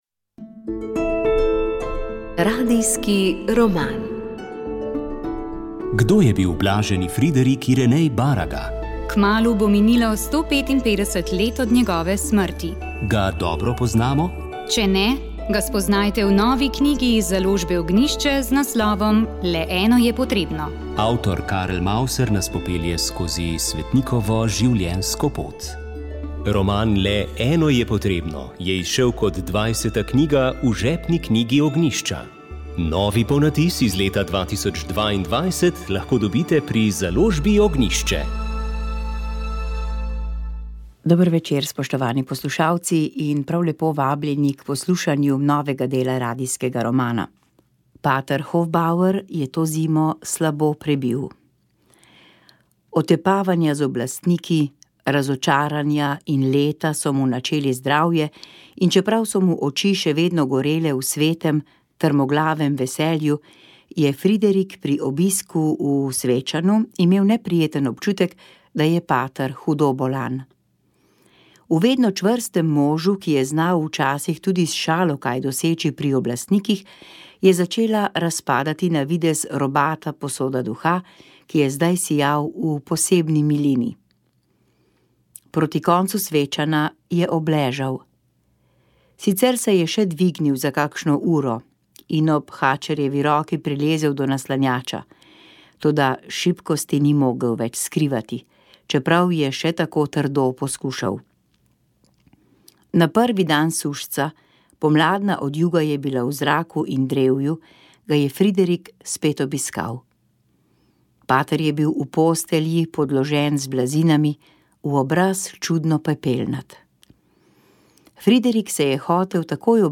Radijski roman